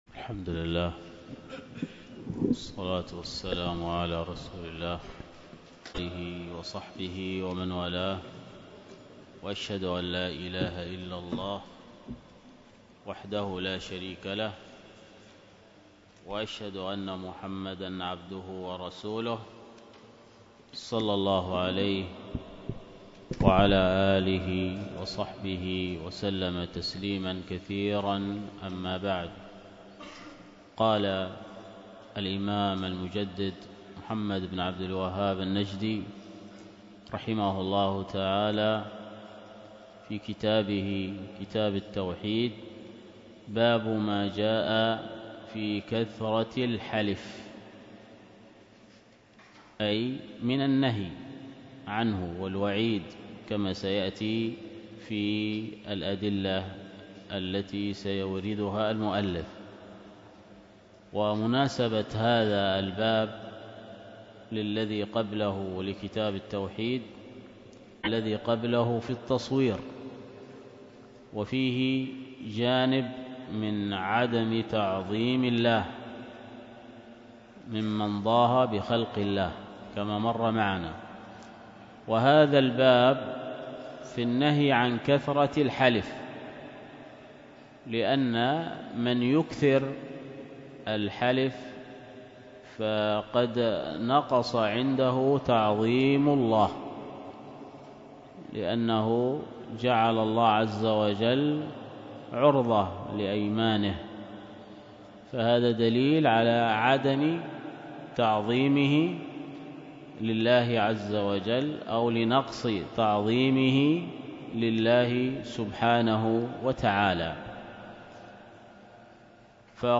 التعليق على التقسيم والتقعيد للقول المفيد ( مكتمل ) | الدروس